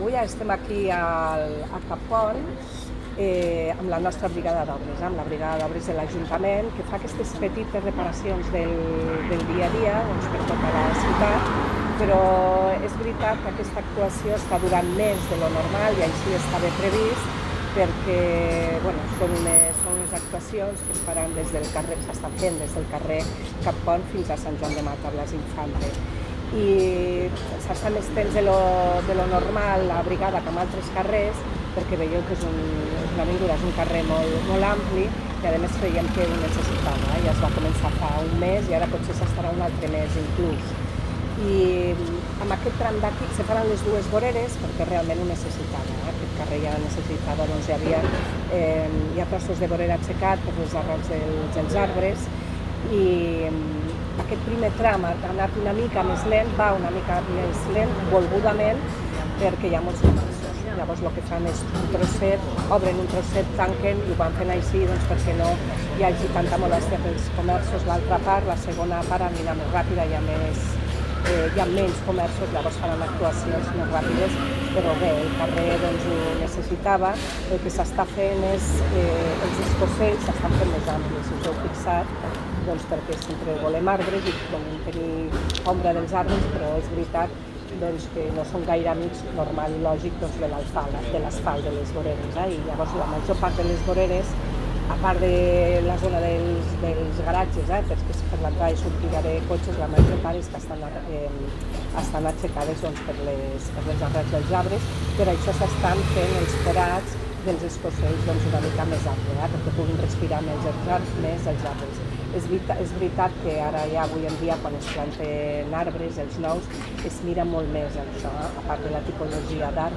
tall-de-veu-de-lalcaldessa-accidental-begona-iglesias-sobre-larranjament-del-paviment-de-lavinguda-valencia.mp3